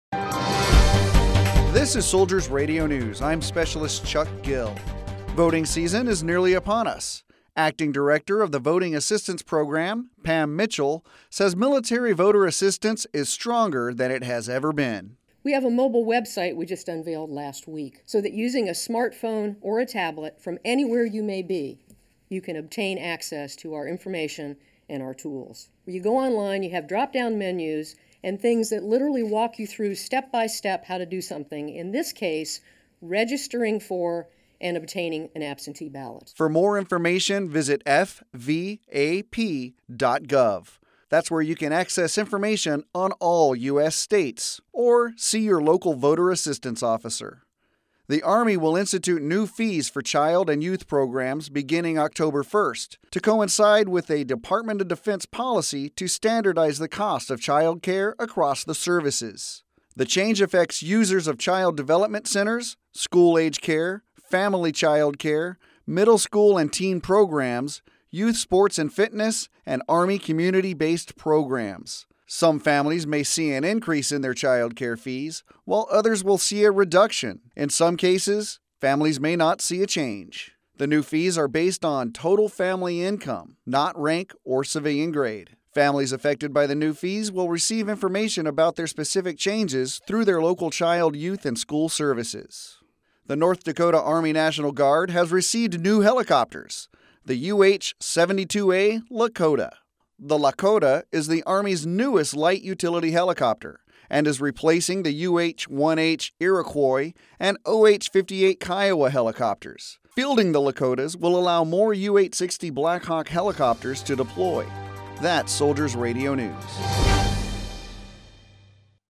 Soldiers Radio News Sept. 7